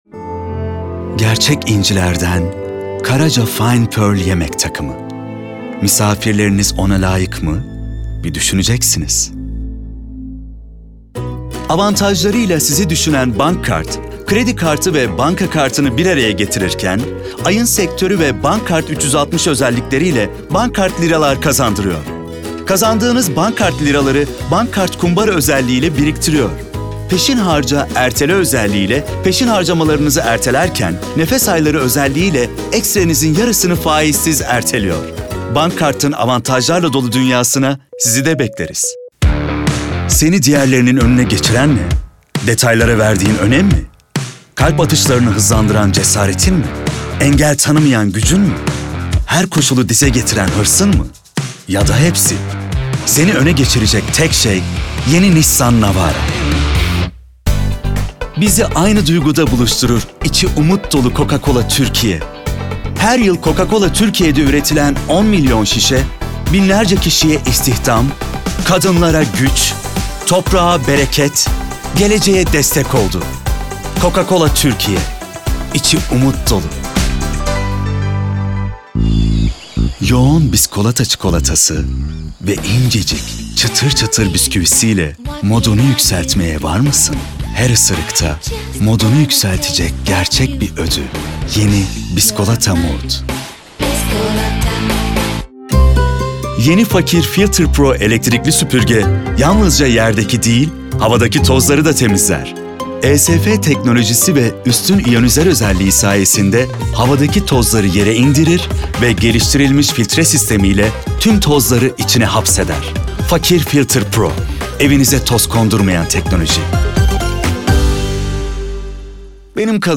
Çeşitli reklamlarda seslendirme yapmıştır.
DEMO SESLERİ
KATEGORİ Erkek
Belgesel, Canlı, Güvenilir, Karakter, Animasyon, Şefkatli, Karizmatik, Promosyon, Dış Ses, Dostane,